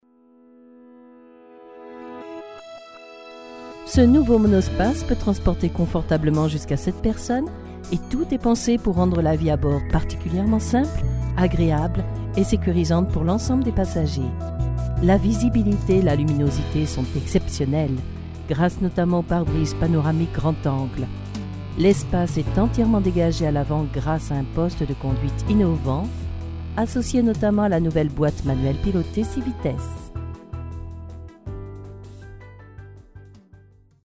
Sprechprobe: Werbung (Muttersprache):
voiceover stamp medium posed particularly like long texts, documentaries and audio books